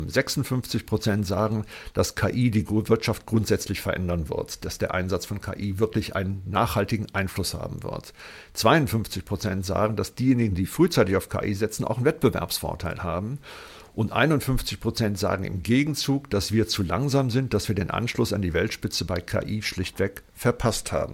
Mitschnitte der Pressekonferenz
pressekonferenz-digitalisierung-der-wirtschaft-2025-ki-veraendert-wirtschaft.mp3